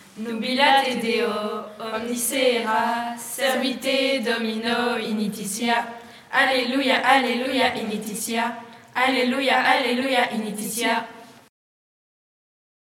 Les Guides de Jambes-Montagne
Type : chant de mouvement de jeunesse | Date : 23 mars 2024